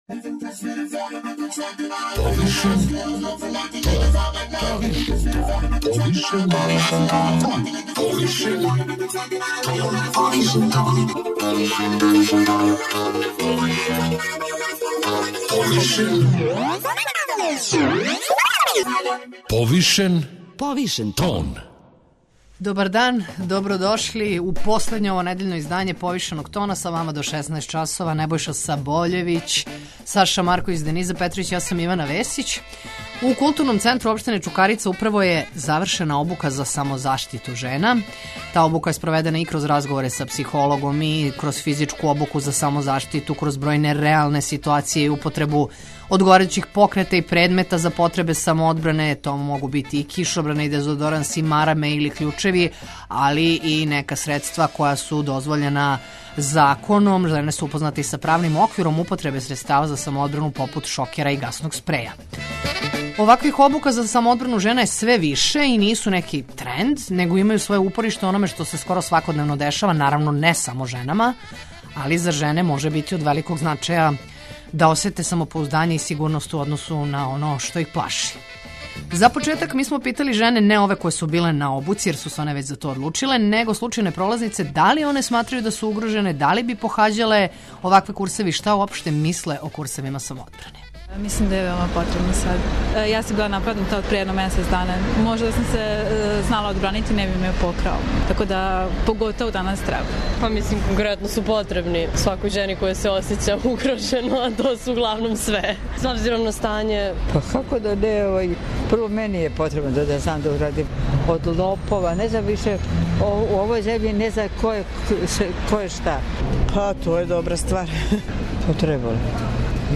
чућете и искуства жена